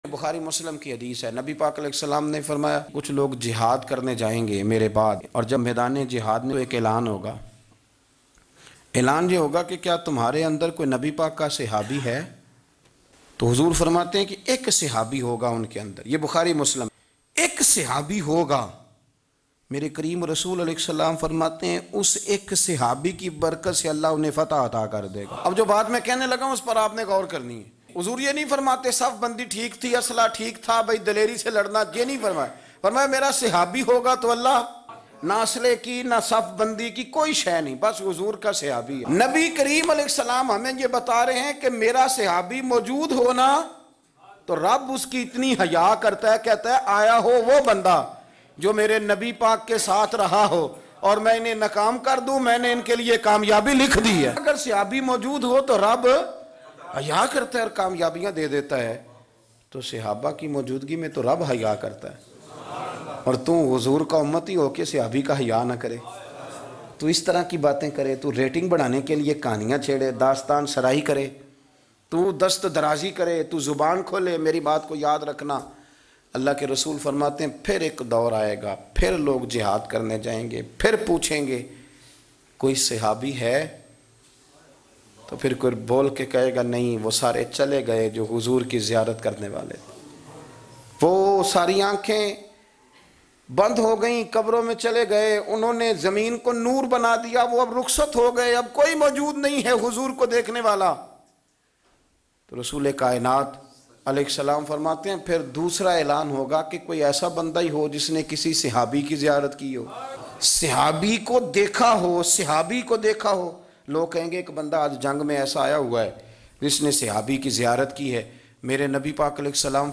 Sahaba Ki Fazilat Bayan MP3 Download in best audio quality.